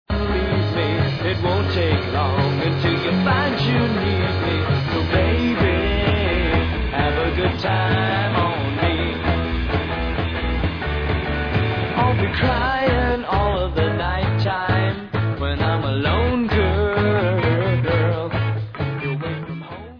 Sixties psychedelia, could be on the "nuggets" box
sledovat novinky v kategorii Pop